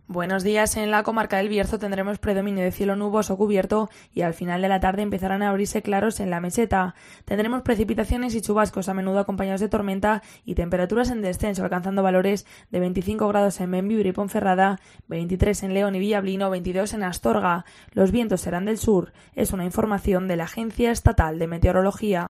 Previsión del tiempo Bierzo